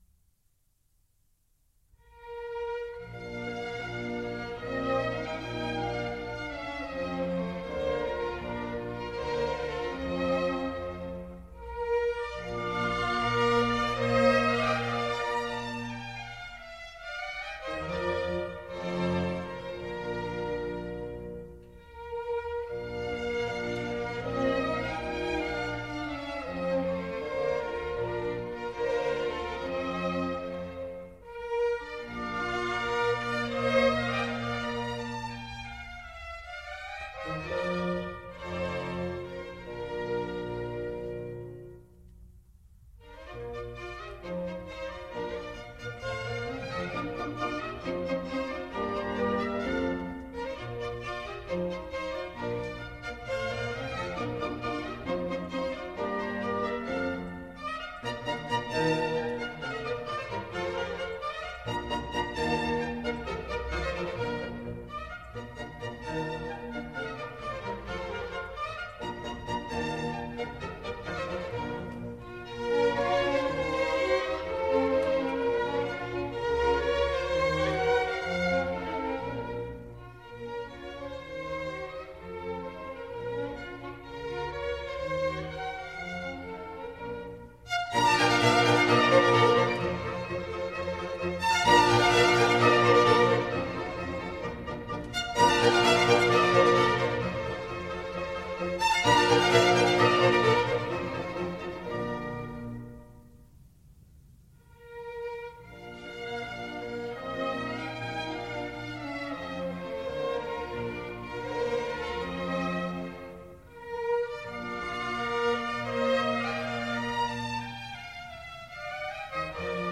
B Flat Major